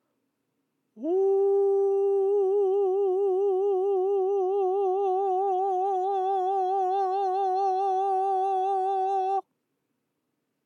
発声しながら喉頭を上げる
音量注意！
上げるパターンも下げるときと同様にただただ声だ響く空間が少し狭くなっただけなので、あまりにも音色が鋭くさせようとしたり平べったくしようとするのは違います。